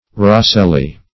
rosselly - definition of rosselly - synonyms, pronunciation, spelling from Free Dictionary Search Result for " rosselly" : The Collaborative International Dictionary of English v.0.48: Rosselly \Ros"sel*ly\, a. Loose; light.